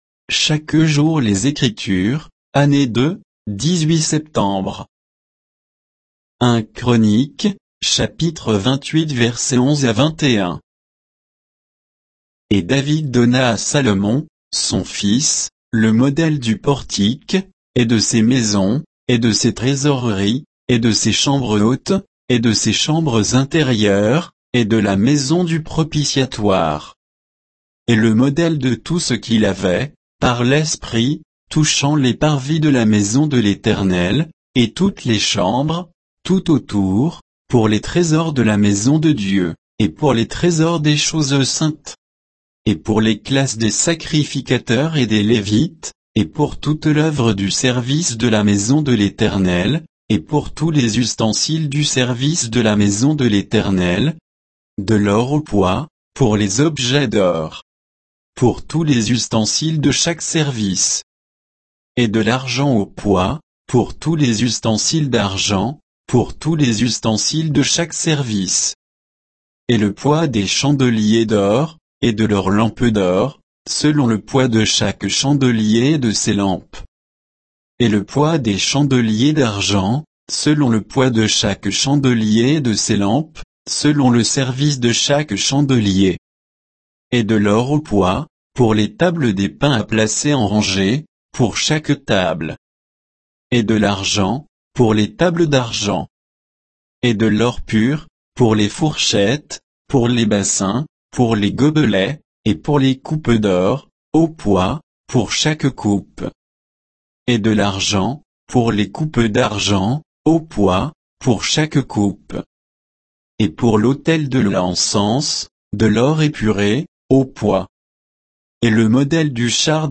Méditation quoditienne de Chaque jour les Écritures sur 1 Chroniques 28